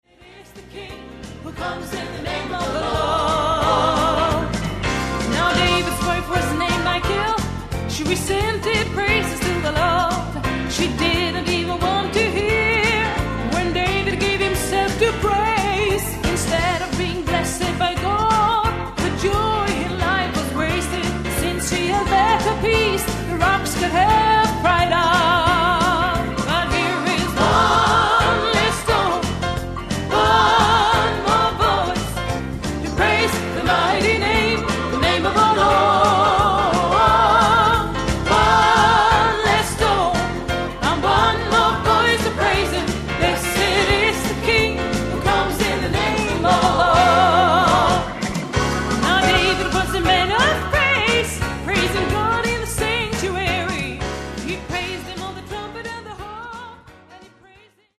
Dom spelade även klaviatur och orgel.